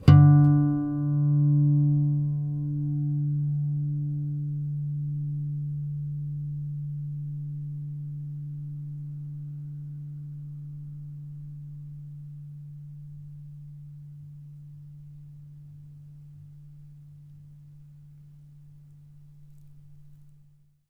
harmonic-01.wav